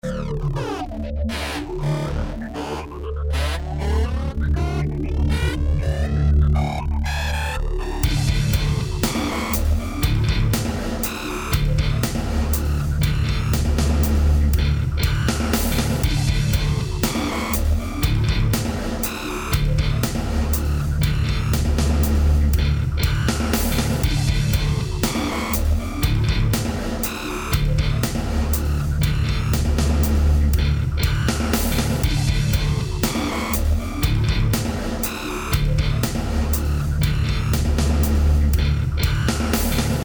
Вложения demo_industrial_alien.mp3 demo_industrial_alien.mp3 939,7 KB · Просмотры: 269